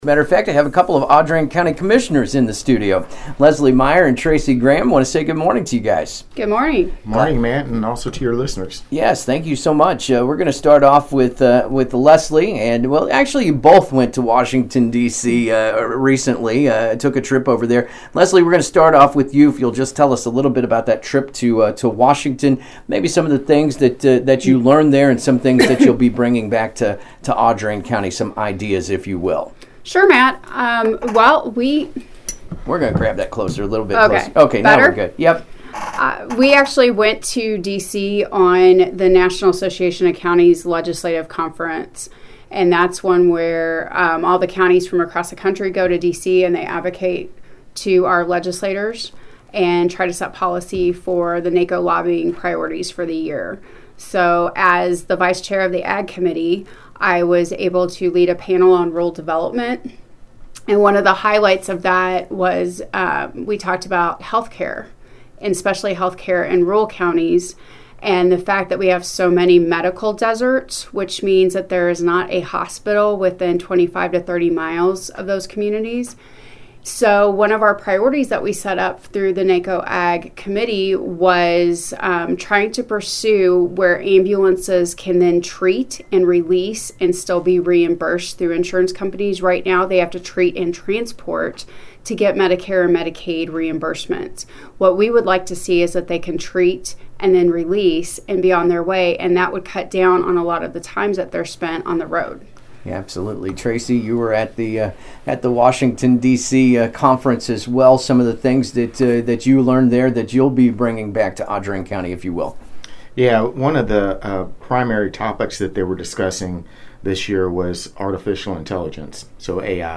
Audrain County Commissioners Leslie Meyer And Tracy Graham Recap National Association Of Counties Legislative Conference On AM 1340 KXEO Am I Awake Morning Show – KXEO
LESLIE-MEYER-TRACY-GRAHAM-INT.mp3